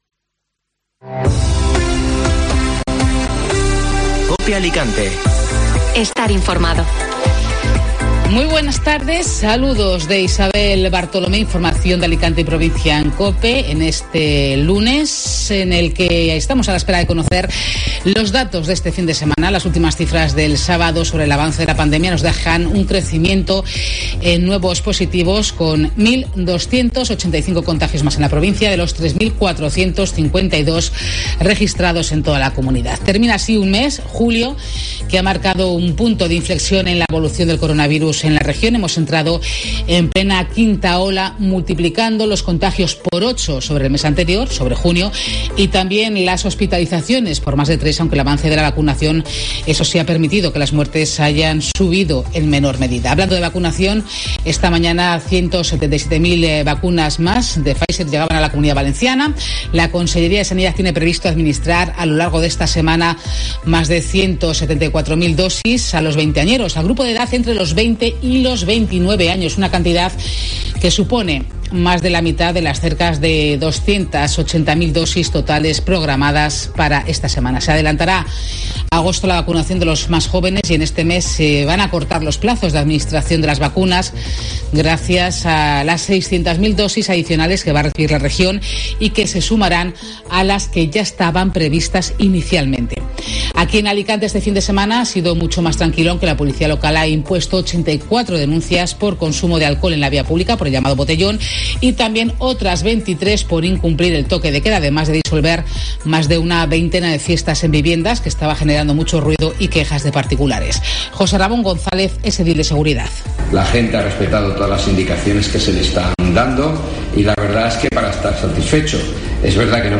Informativo Mediodía COPE (Lunes 2 de agosto)